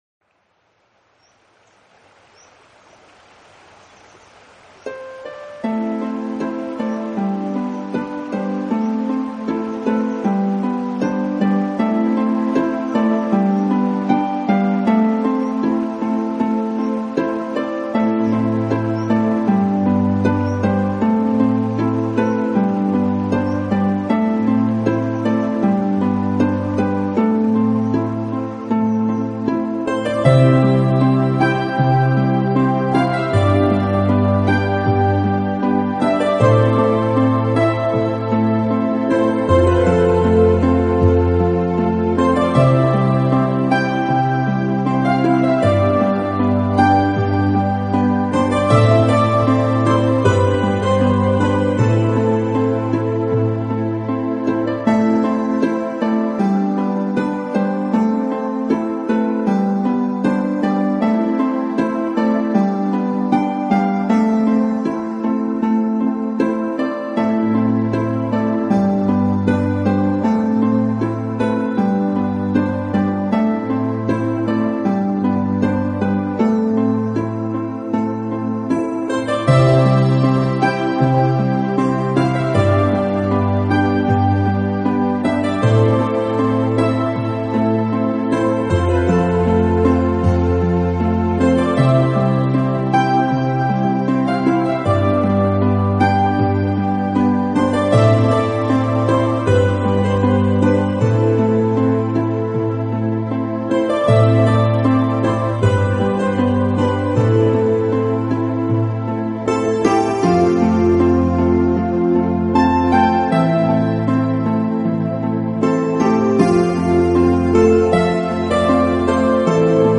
充满了朦胧的气氛，为人们带来内心的平静。